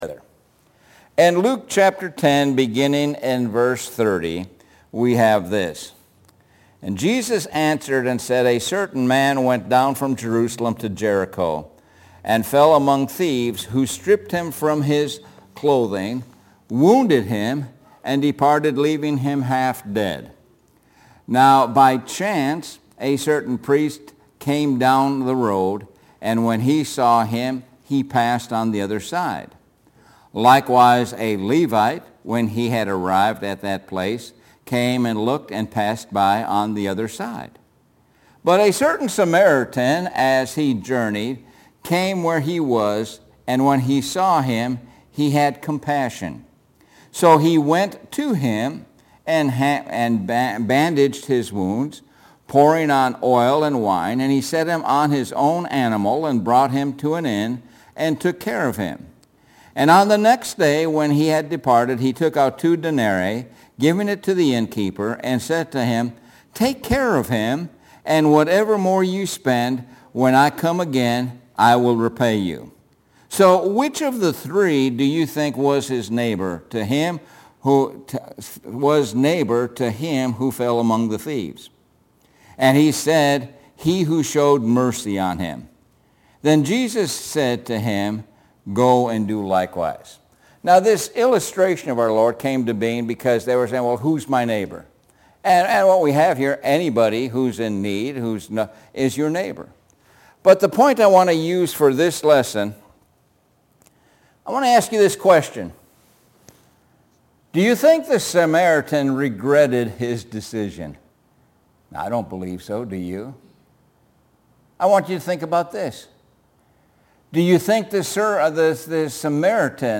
Sun PM Sermon